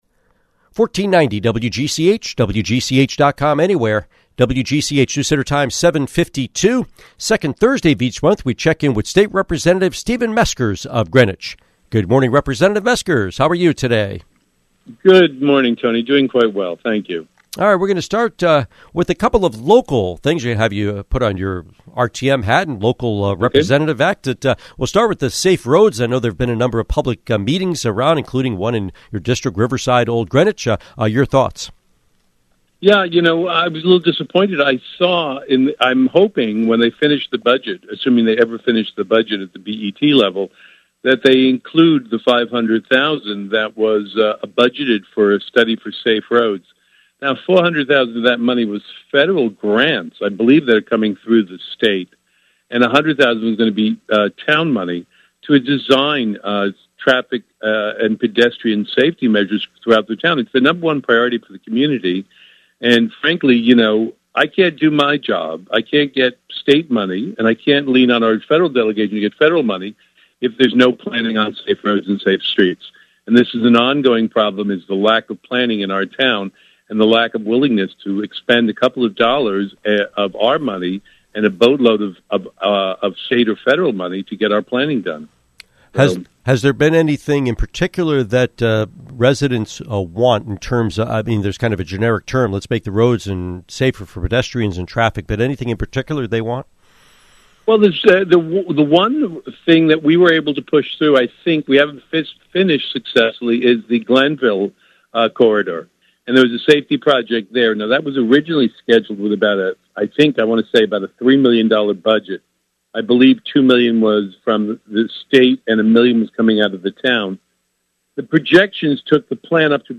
State Representative Stephen Meskers